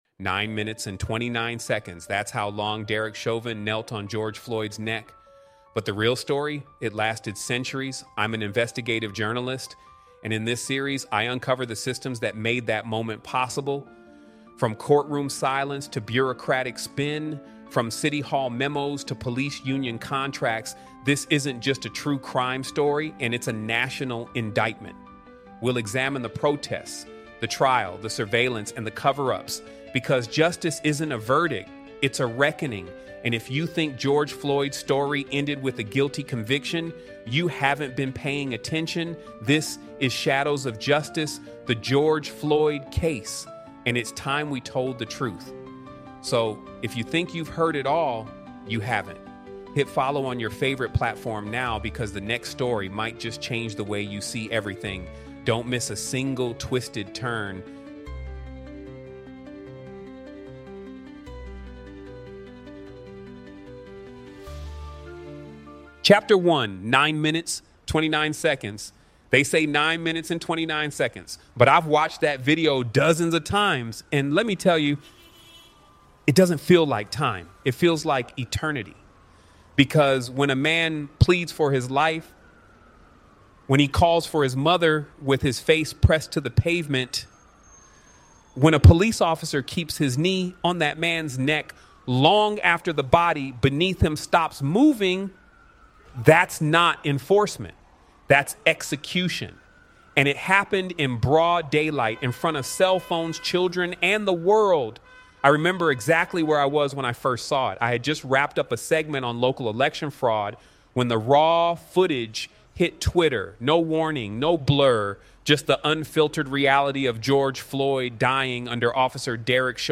Shadows of Justice: The George Floyd Case is a groundbreaking, chaptered true crime investigative audiobook that peels back the layers of one of the most pivotal cases in American history. Told through the voice of a veteran male journalist, this series unveils the corruption, conspiracy, and institutional injustice surrounding George Floyd’s murder — going far beyond the viral video.